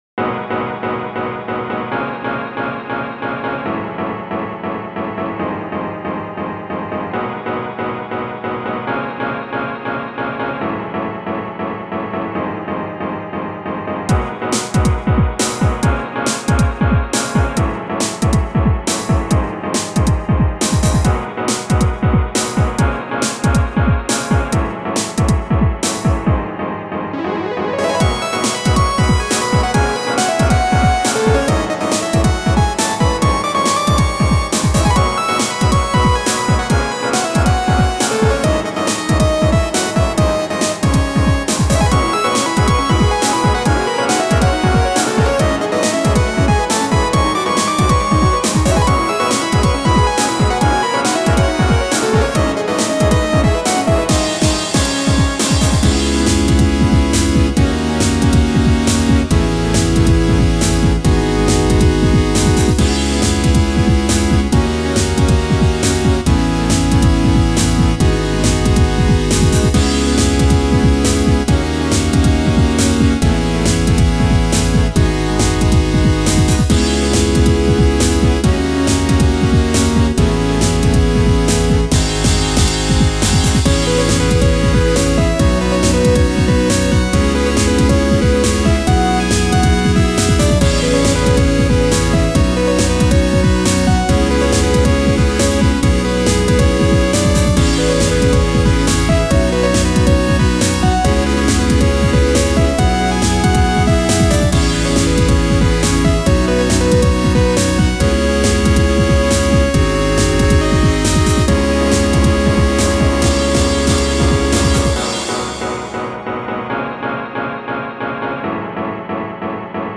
サイバー系の音楽。これも電気系統のにおいがするなー。